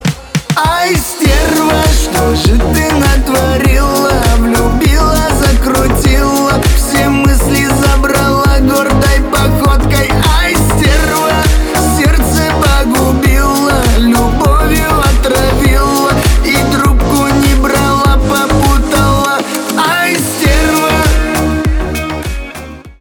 поп
битовые